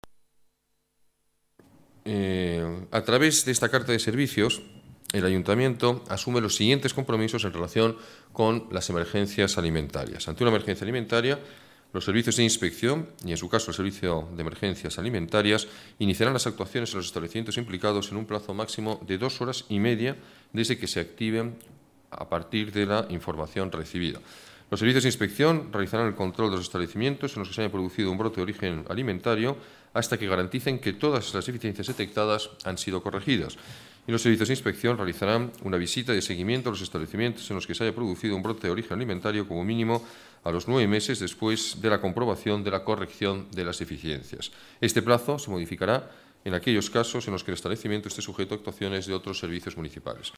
Nueva ventana:Declaraciones del alcalde, Alberto Ruiz-Gallardón: carta servicios seguridad alimentaria